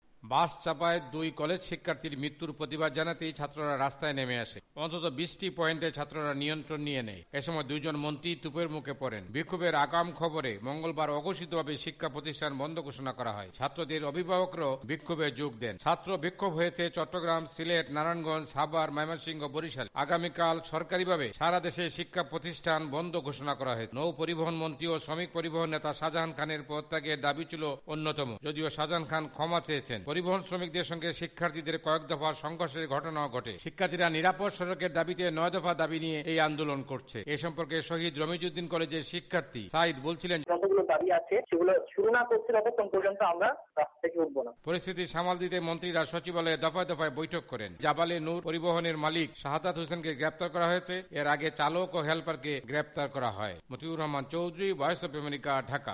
প্রতিবেদন